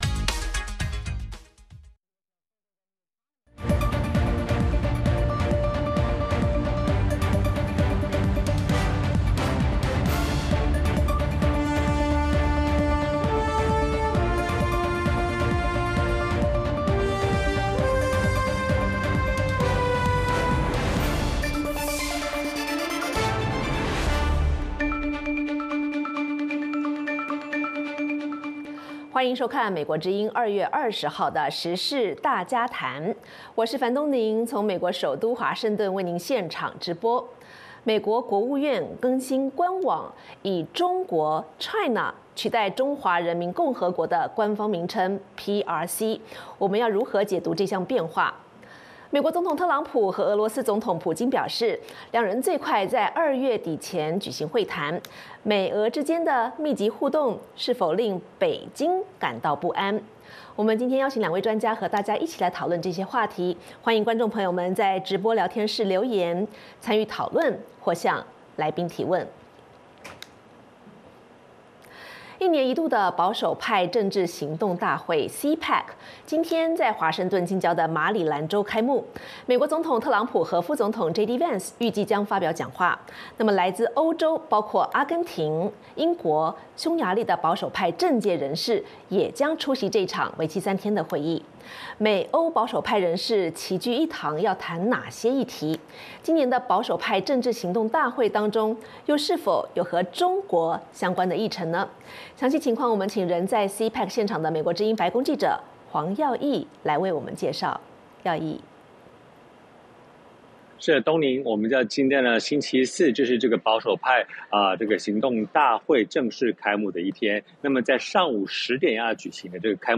美国之音中文广播于北京时间晚上9点播出《时事大家谈》节目(电视、广播同步播出)。《时事大家谈》围绕重大事件、热点问题、区域冲突以及中国内政外交的重要方面，邀请专家和听众、观众进行现场对话和讨论，利用这个平台自由交换看法，探索事实。